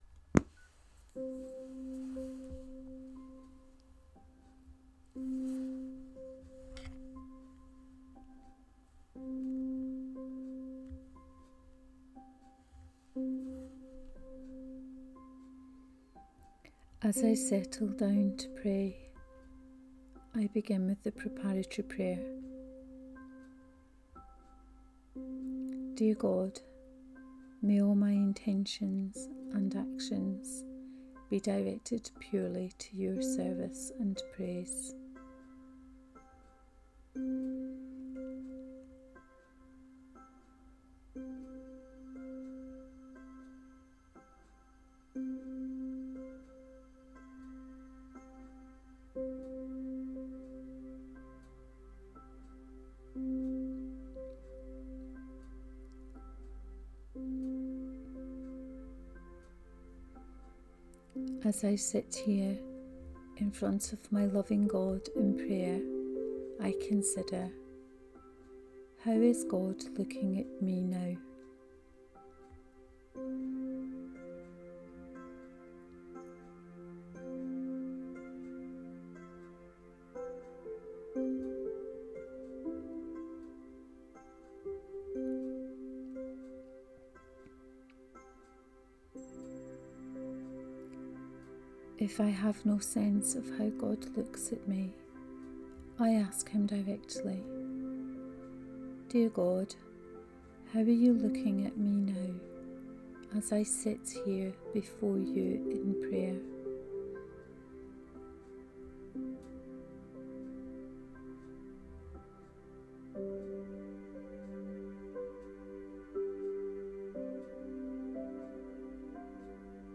A guided prayer, praying with images, using the stucture of an Ignatian prayer period. An Imaginative contemplation prayer from Matthew's gospel for the Sunday lectionary.